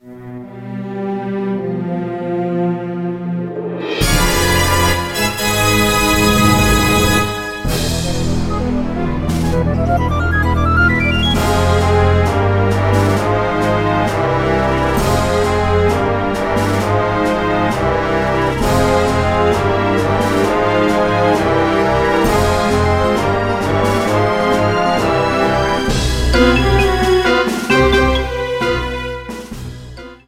boss battle music